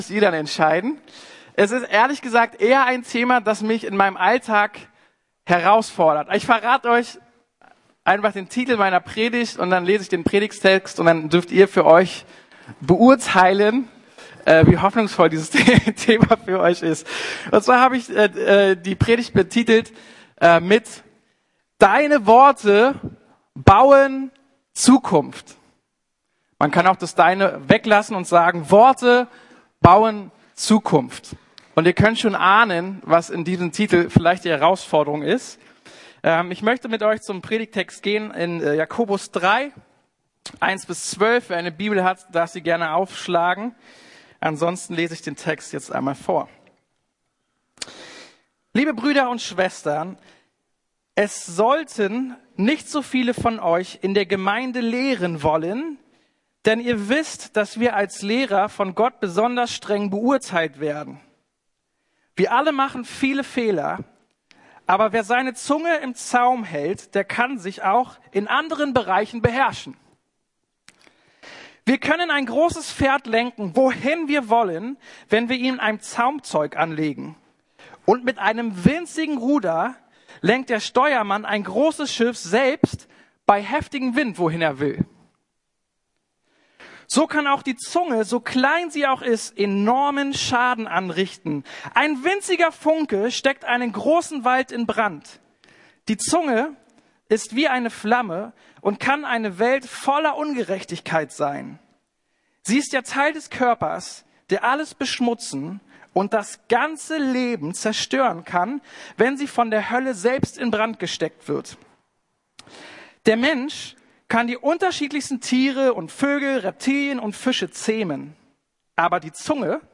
Deine Worte bauen Zukunft ~ Predigten der LUKAS GEMEINDE Podcast